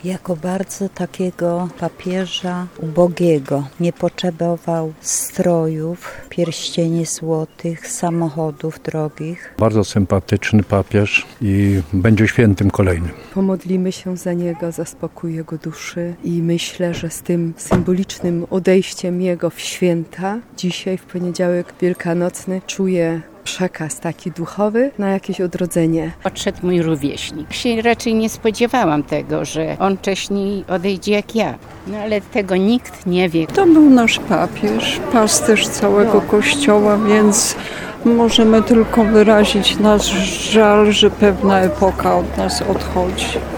W intencji zmarłego Ojca Świętego modlono się między innymi w tarnowskiej Bazylice Katedralnej. Zgromadzeni tam wierni przyznawali, że będą wspominać go jako Papieża, który był zawsze blisko ludzi, a datę jego odejścia traktują w sposób symboliczny.